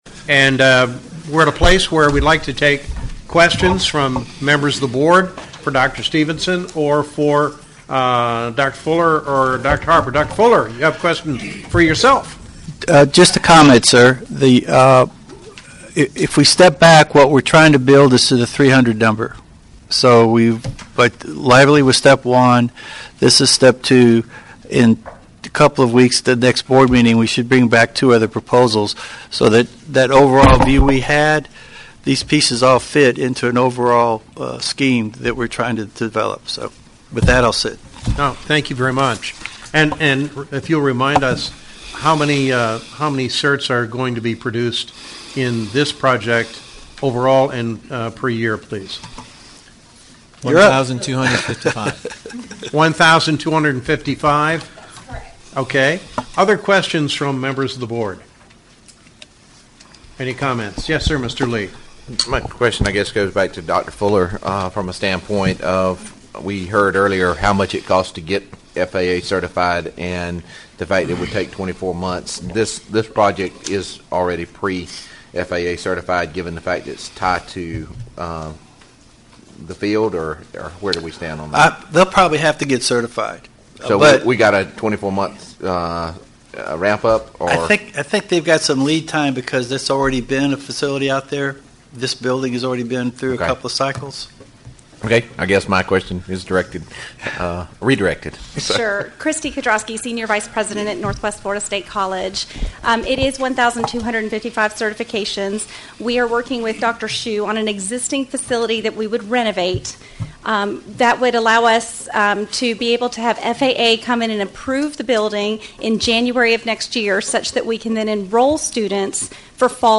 The meeting will be held in Building A, Gulf-Franklin Campus of Gulf Coast State College, 3800 Garrison Avenue, Port St. Joe, Florida 32456.
(Please note that there were technical difficulties with the remaining recording.)
Board-Meeting-Part2-v2.mp3